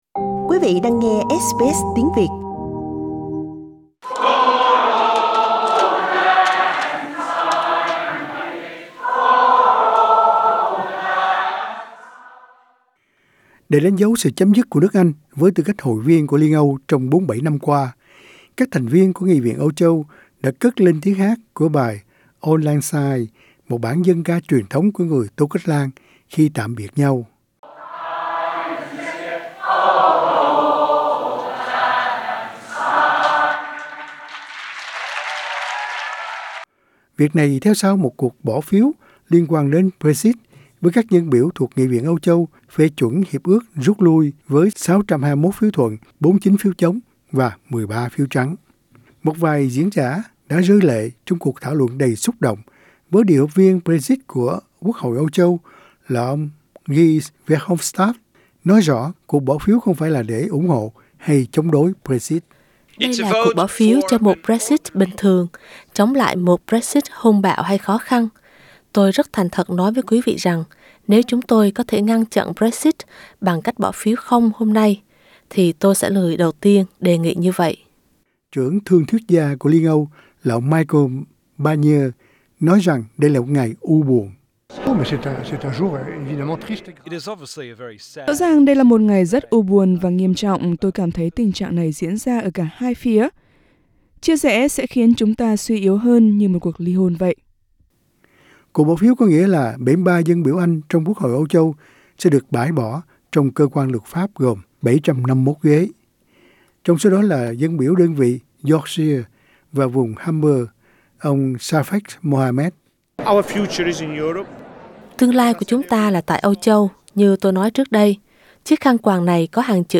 Members of EU Parliament sing Auld Lang Syne during plenary session on BREXIT vote Source: AAP